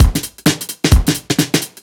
OTG_Kit 5_HeavySwing_130-D.wav